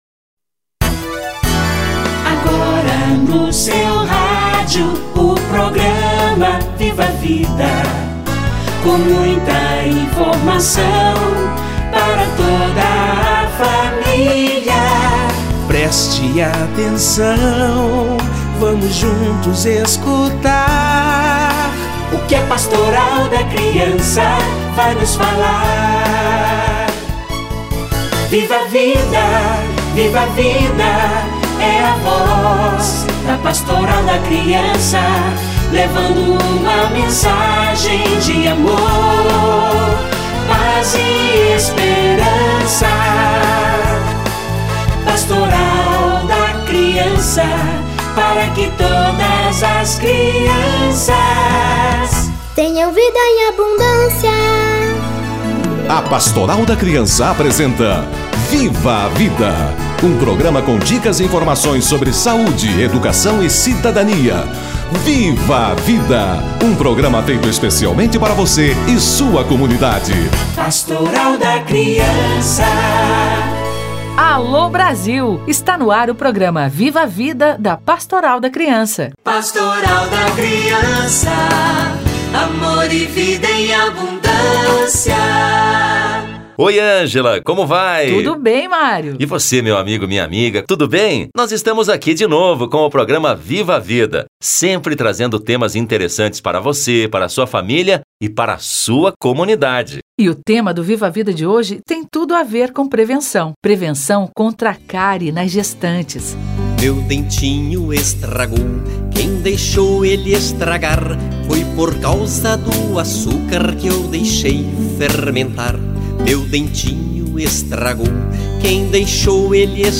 Saúde bucal da gestante - Entrevista